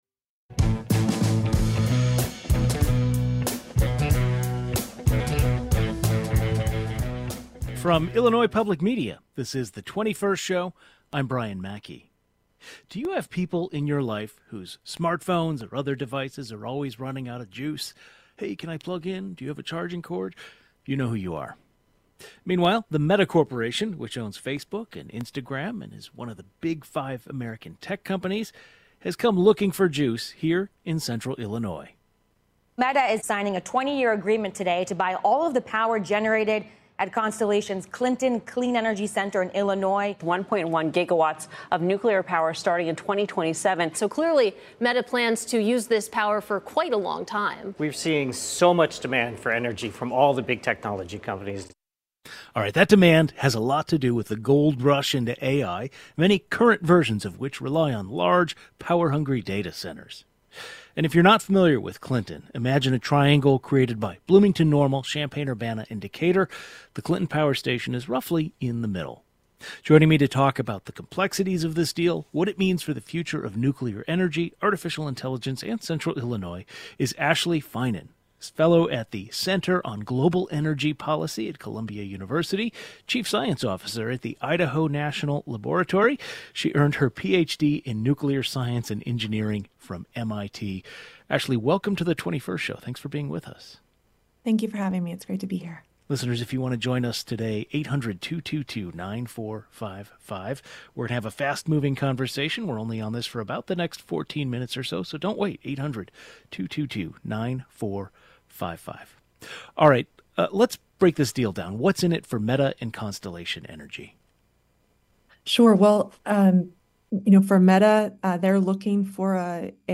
An energy expert discusses the complexities of this agreement, what it means for the future of nuclear energy, artificial intelligence, and the region.